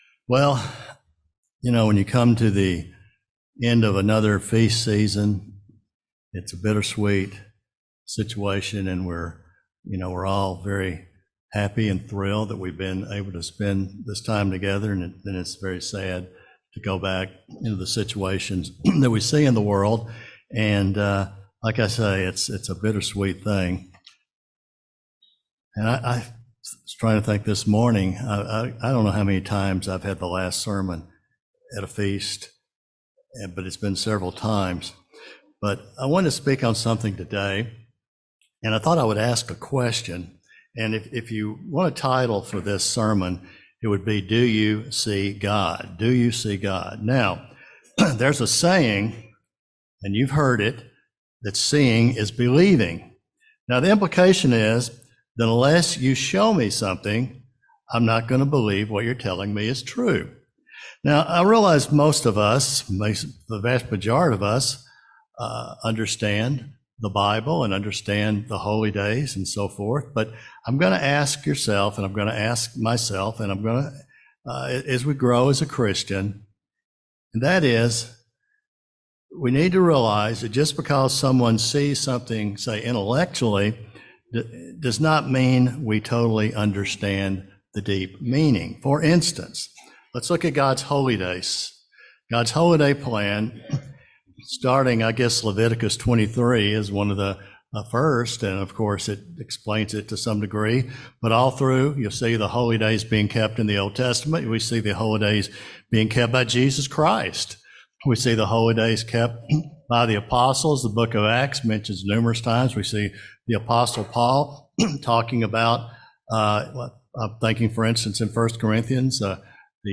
This sermon was given at the Lake Geneva, Wisconsin 2023 Feast site.